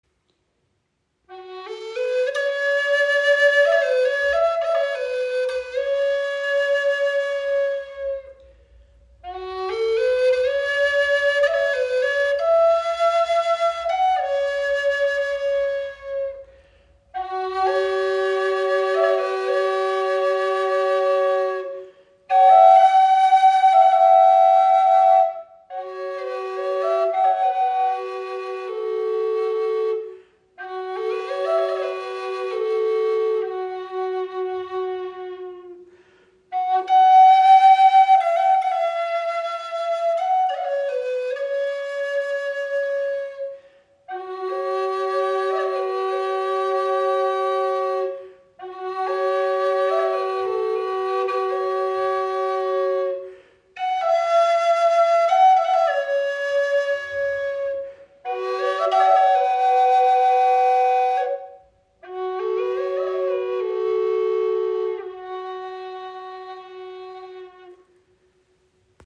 Doppelflöte in G - 432 Hz im Raven-Spirit WebShop • Raven Spirit
Klangbeispiel
Dies ist eine wundervolle, rustikale Doppelflöte, welche auf G Moll in 432 Hz gestimmt ist. Sie erzeugt eine magisch wirkende, warme Klangfarbe.